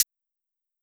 Closed Hat (Miss Me).wav